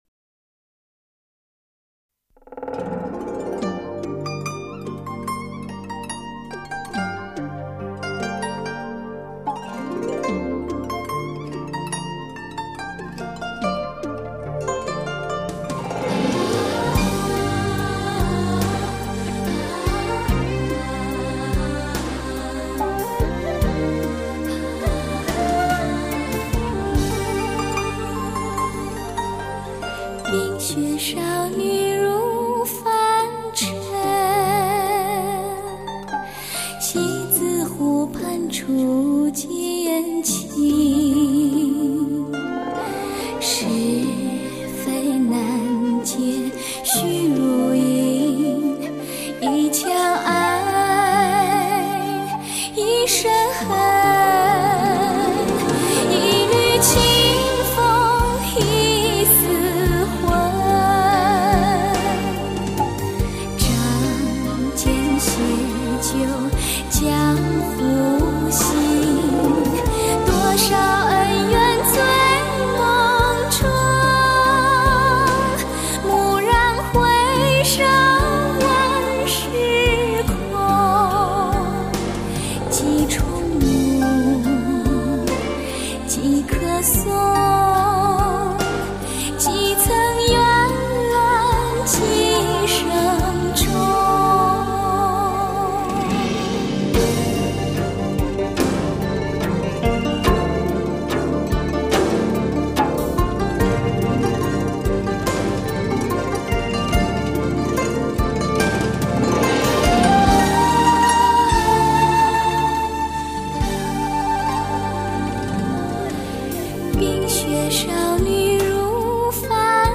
音乐类别：游戏原声